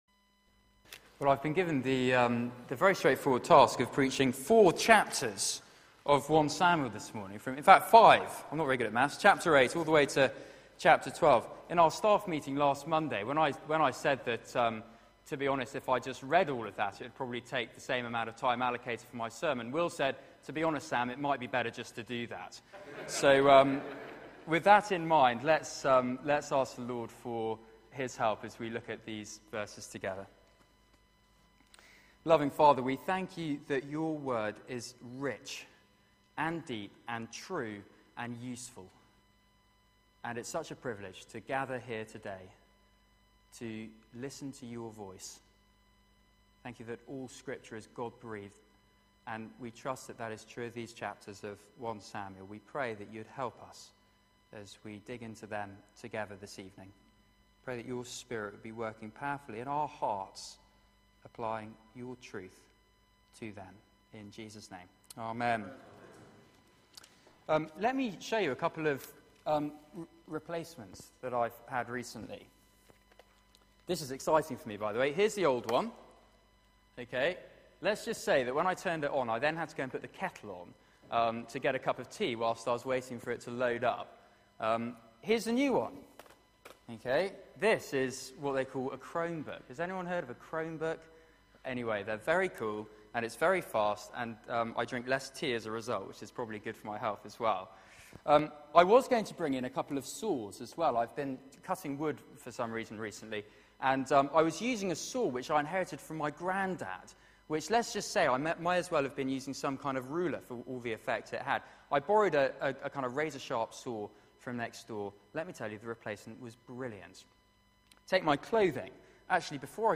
Media for 6:30pm Service on Sun 01st Jun 2014 18:30